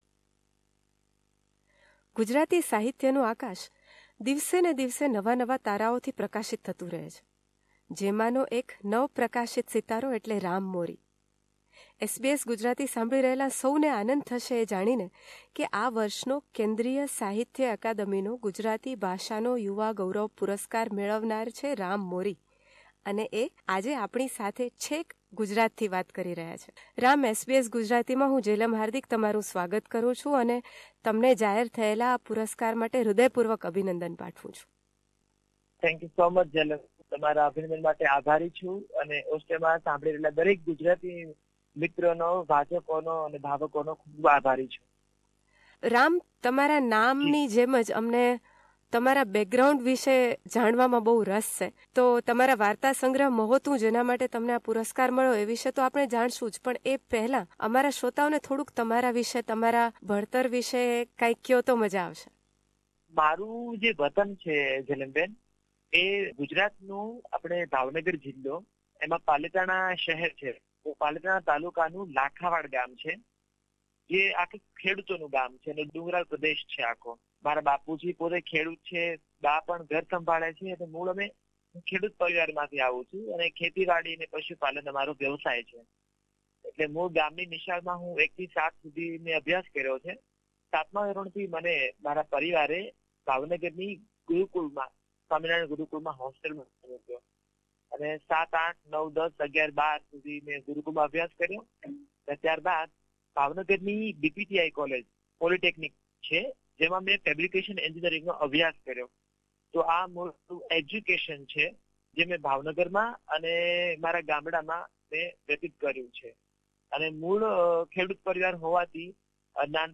candid conversation